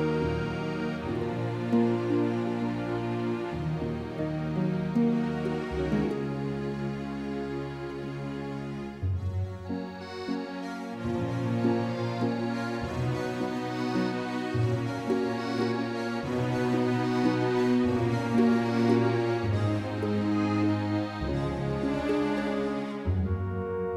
Cut Down Version with Whistling Soundtracks 1:52 Buy £1.50